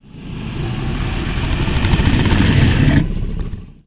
c_hydra_bat1.wav